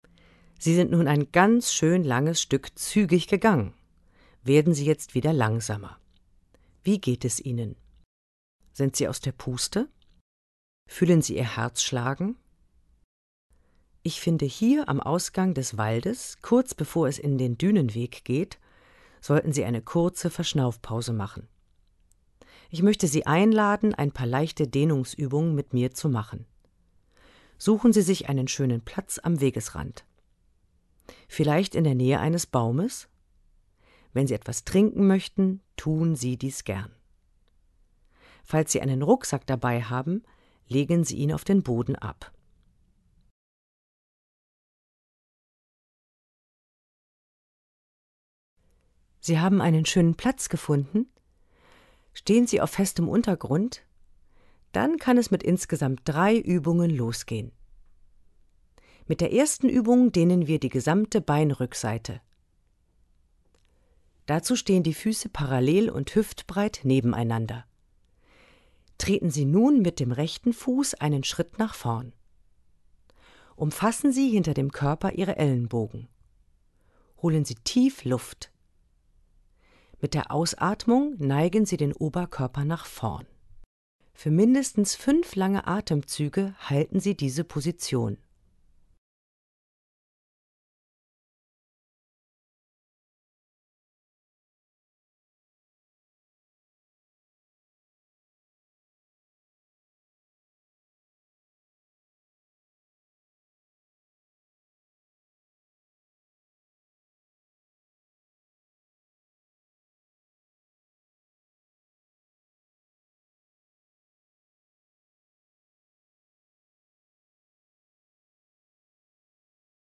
Audioguide
Verantwortlich für die Tonaufnahmen: Tonstudio an der Hochschule Stralsund.